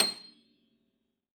53f-pno26-C6.aif